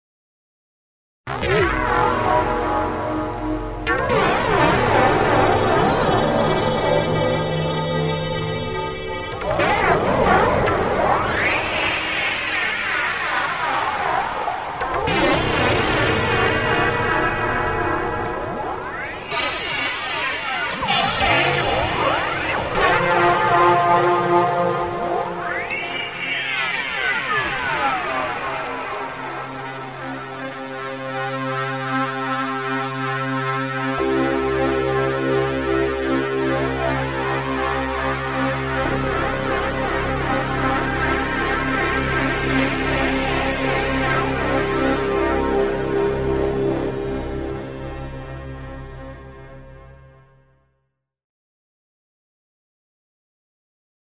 Ringmod effect demo